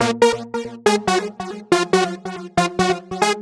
描述：肉质恍惚的合成器
Tag: 140 bpm Trance Loops Synth Loops 590.71 KB wav Key : Unknown